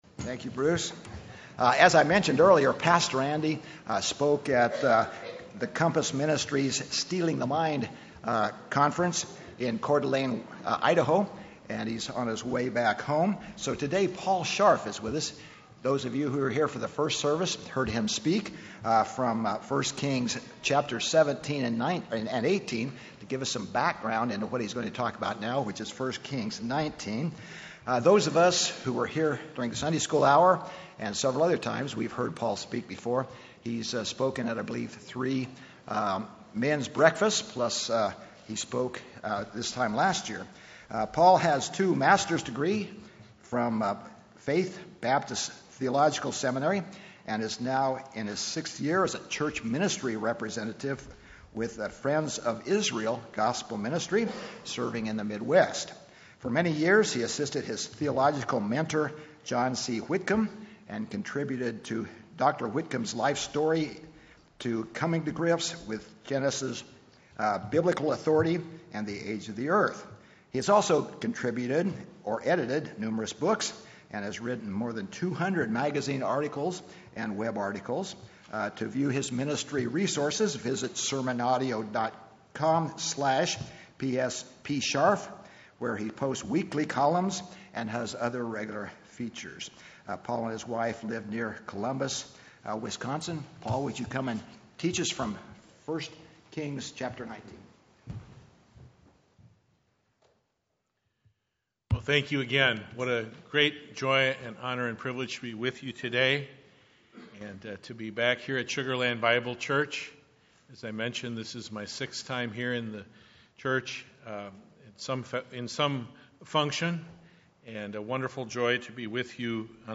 Guest Speaker